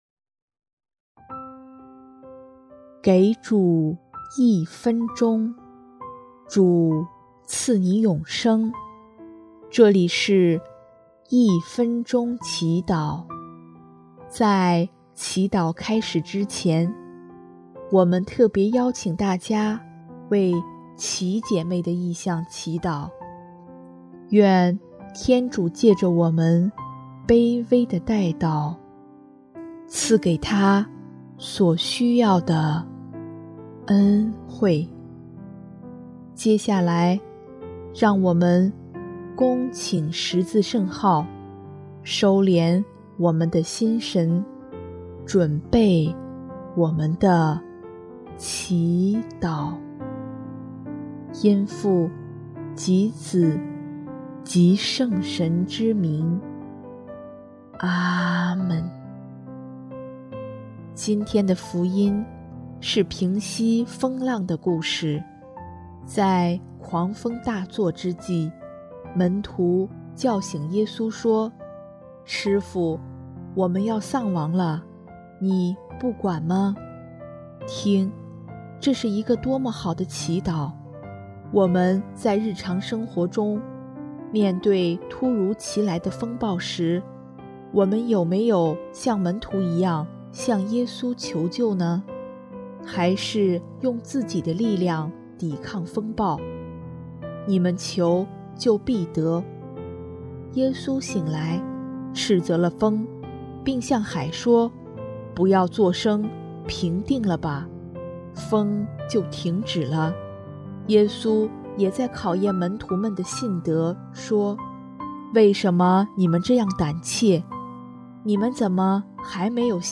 音乐： 主日赞歌《凡求的就必得到》